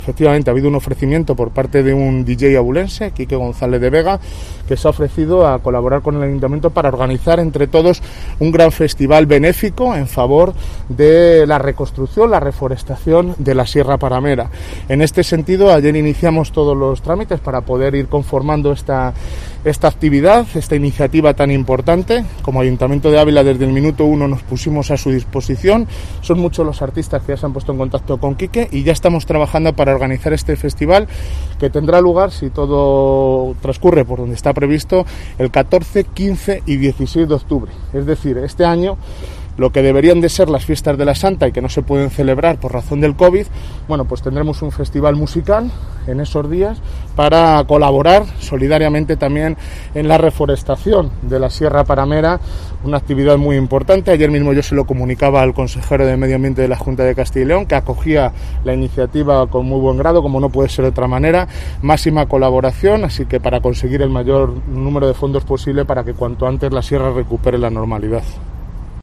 El alcalde, Jesús Manuel Sánchez Cabrera sobre el festival solidario